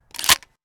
weapon_foley_pickup_07.wav